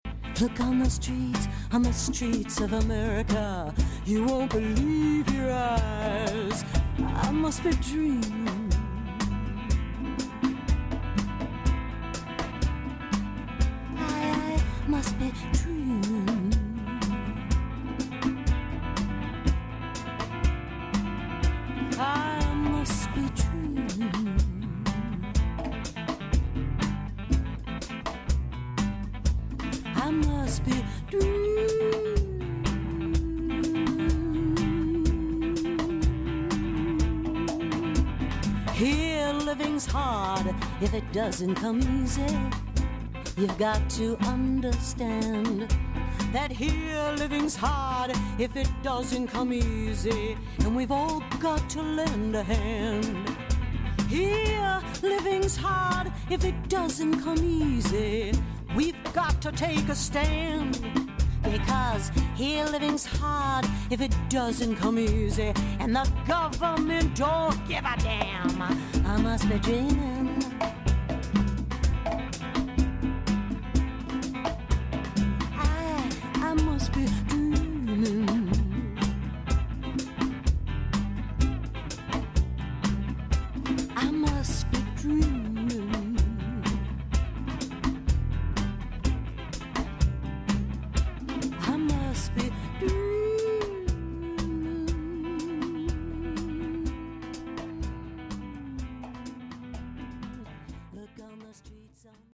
vocals/piano
bass
drums
guitar
congas
maracas
harmonica